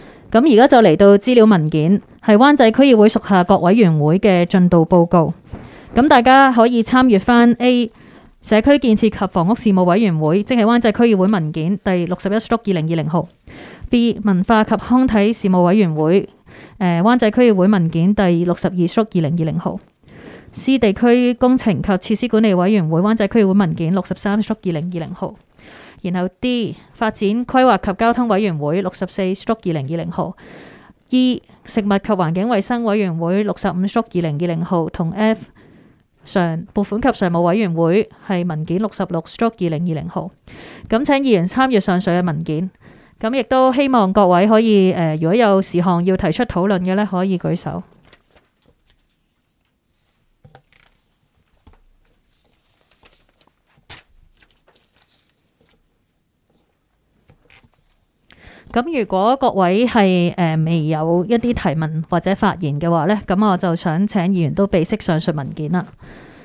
区议会大会的录音记录
湾仔民政事务处区议会会议室